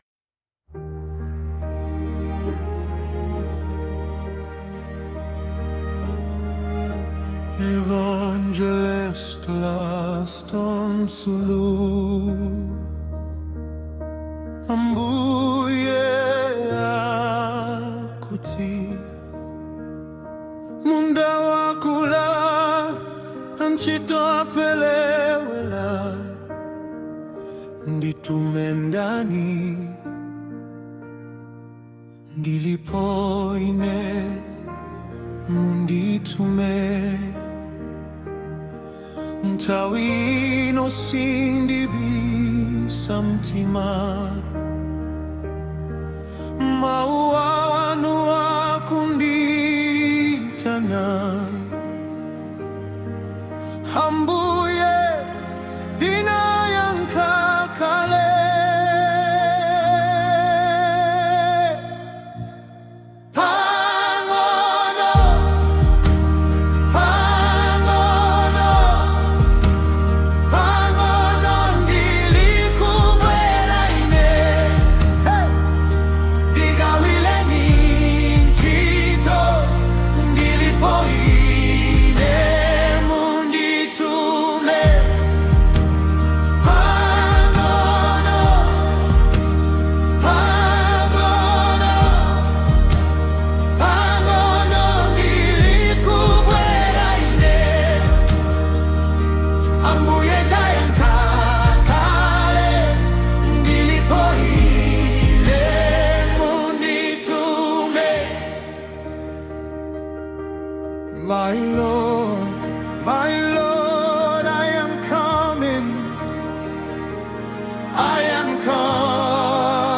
Gospel
gospel song